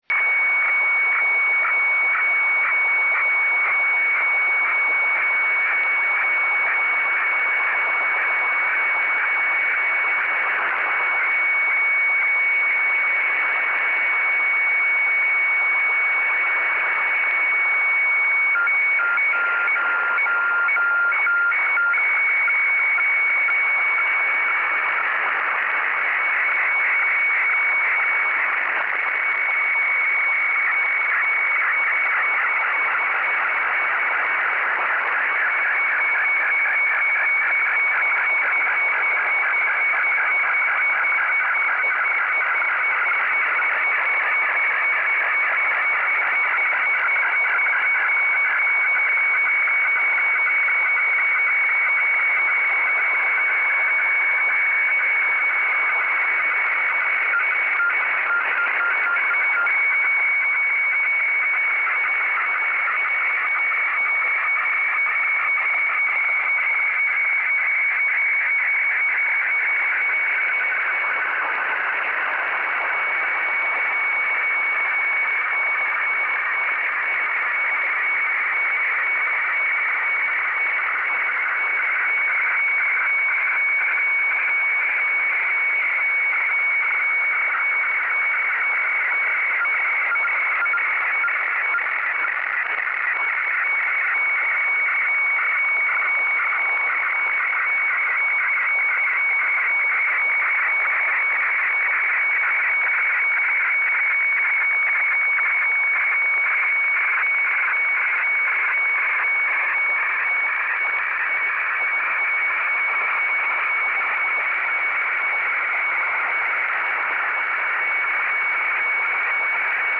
Sound_Sample_WeFAX.mp3